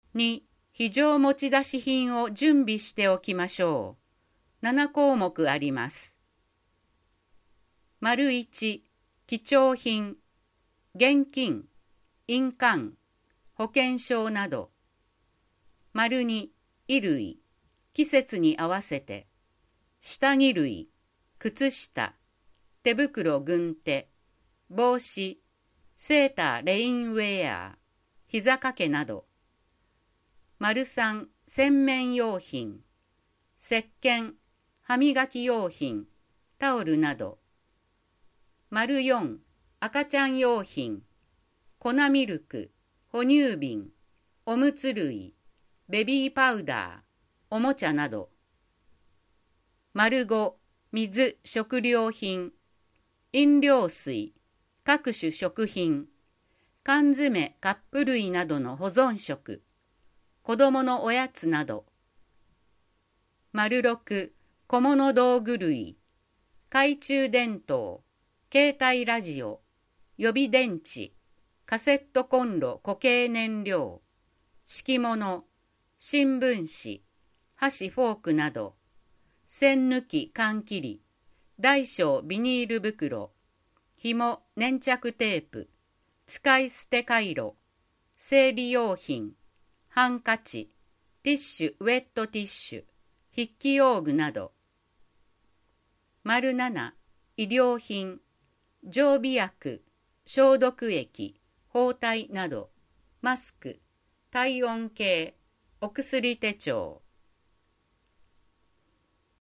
豊中市総合ハザードマップ音訳版(1)1.災害を知る～3.大雨時のとるべき行動